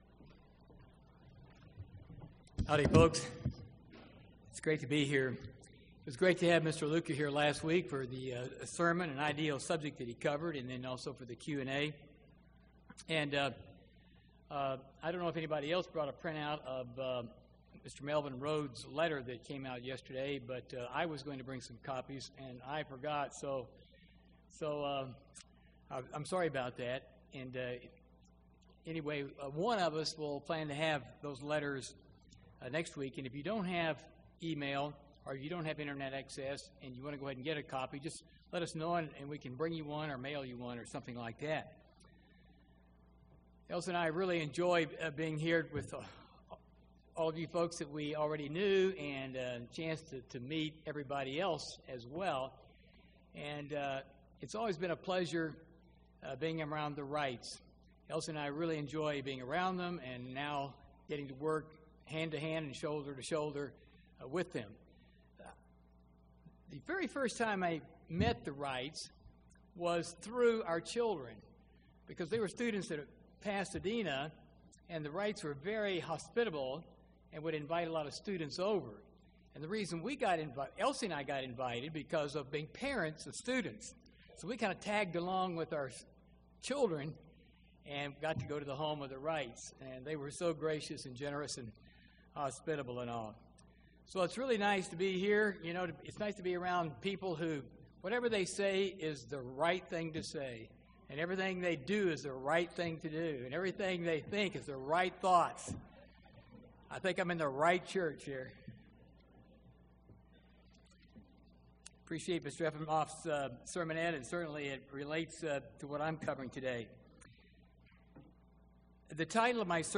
This sermon is # 1 of a two-part series on authority/submission—one helps to understand the other. Respect for and submission to authority is a major component of godly character.